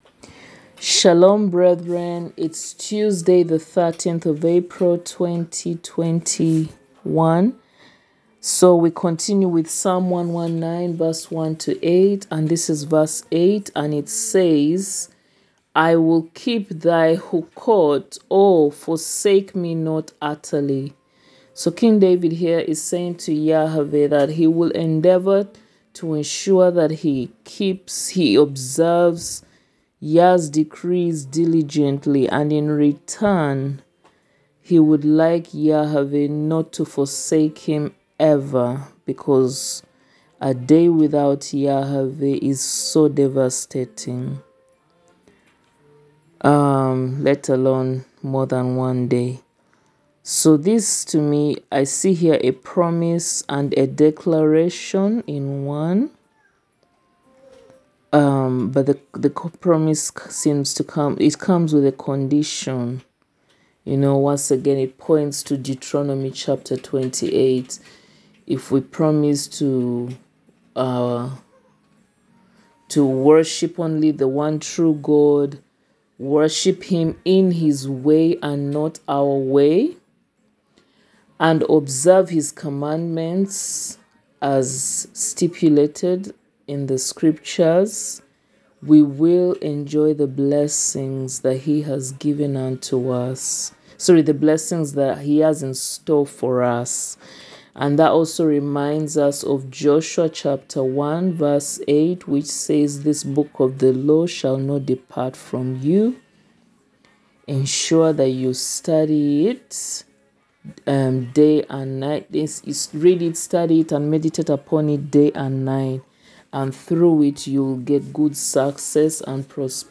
The Teaching in Audios: